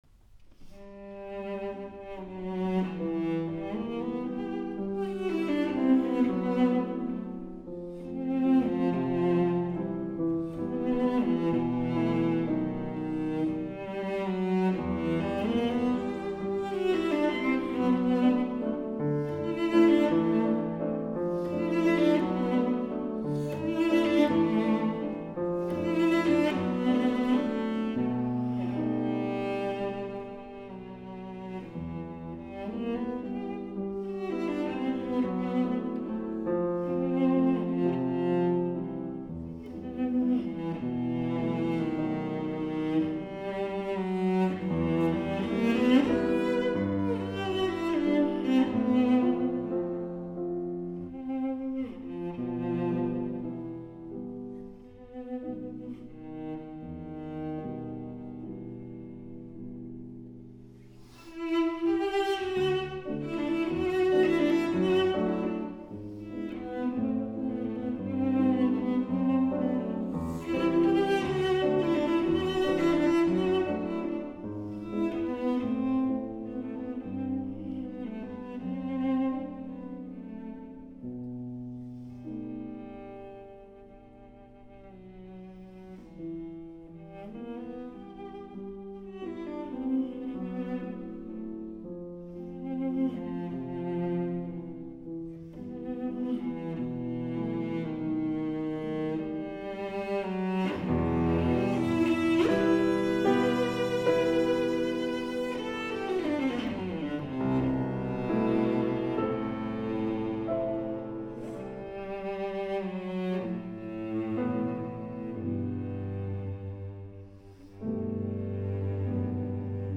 Wigmore live
cello
piano